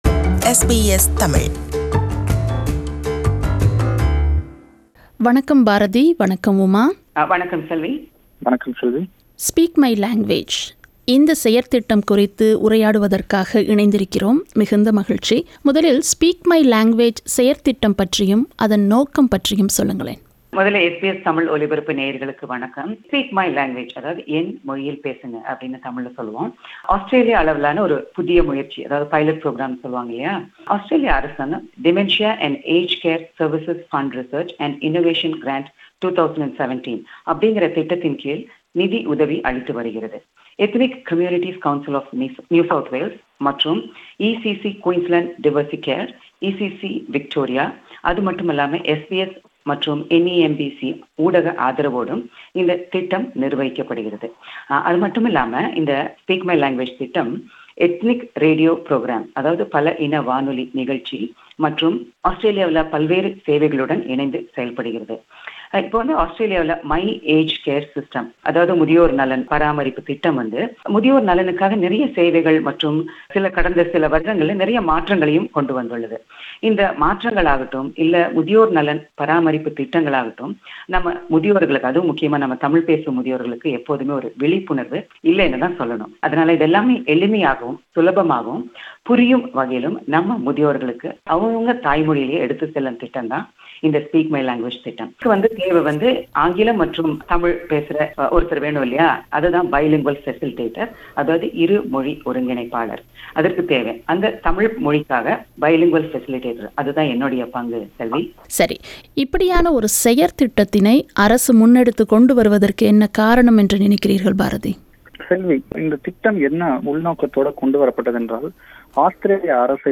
"Speak My Language" - Radio Conversations About Ageing Well